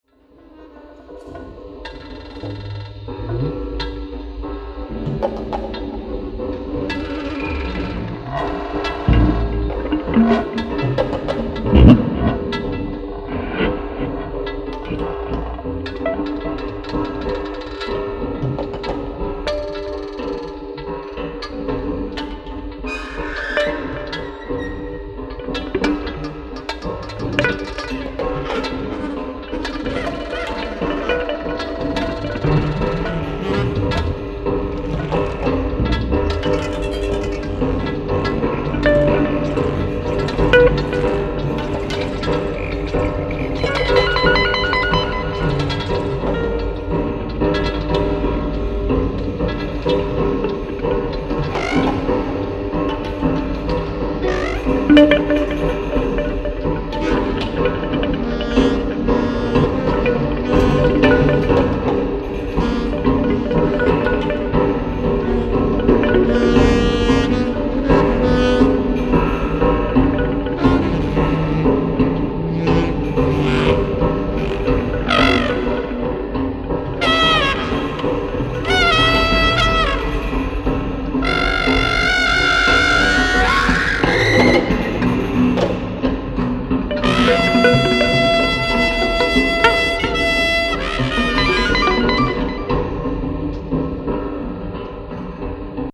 地球外イメージかき立てる、霊感山勘みなぎる謎めいた即興空間。
キーワード：霊性　地球外　即興